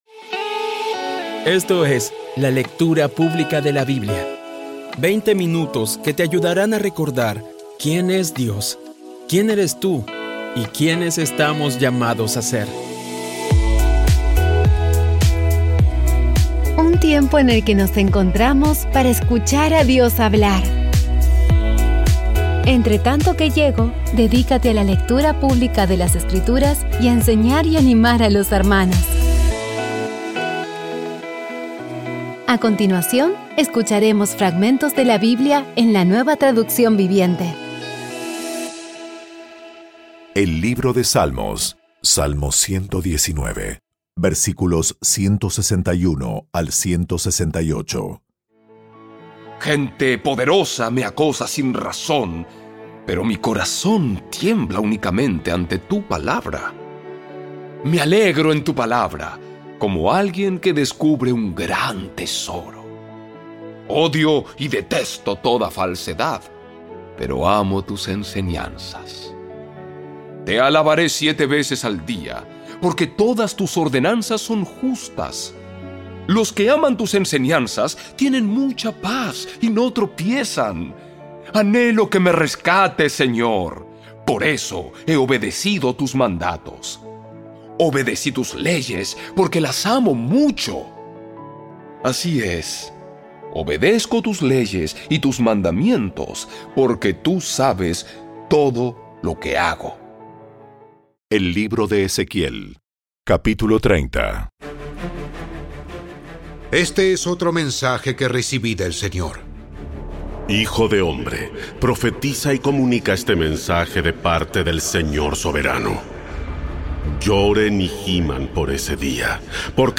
Audio Biblia Dramatizada Episodio 316
Poco a poco y con las maravillosas voces actuadas de los protagonistas vas degustando las palabras de esa guía que Dios nos dio.